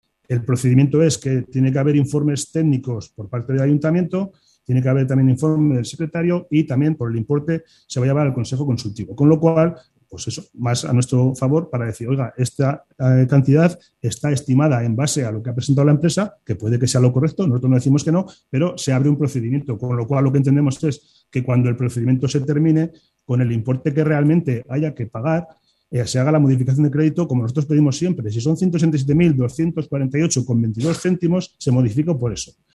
PLENO-2_.mp3